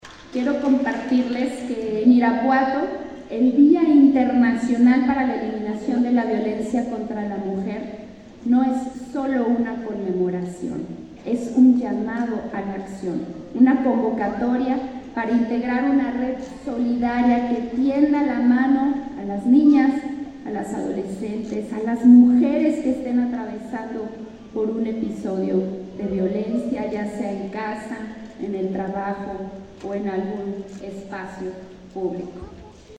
Irapuato, Gto. 25 de noviembre del 2024 .- Durante el evento ‘No estás sola’ para conmemorar el Día Internacional para la Eliminación de la Violencia contra las Mujeres, el Instituto Municipal de las Mujeres Irapuatenses (Inmira) entregó distintivos a las instituciones, empresas y asociaciones civiles que se han sumado a las estrategias municipales para combatir la violencia.
Valeria Alfaro, presidenta del DIF Municipal